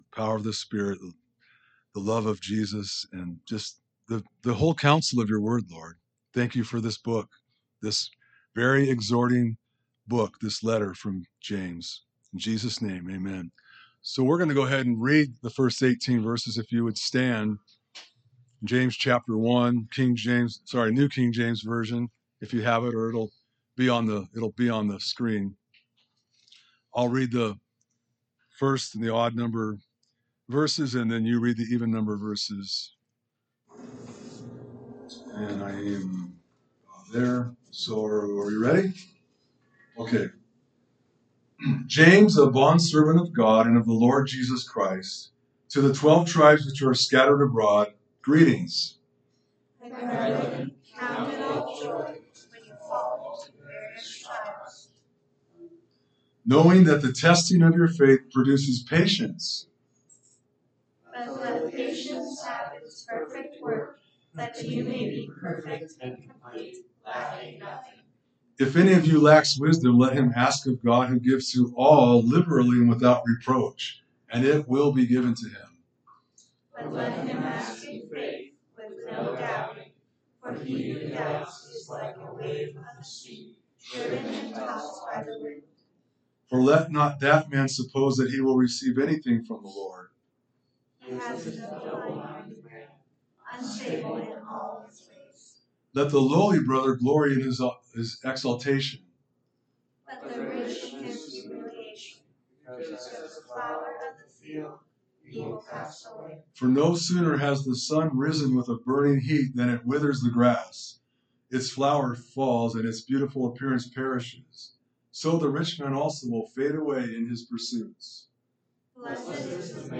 A message from the series "James."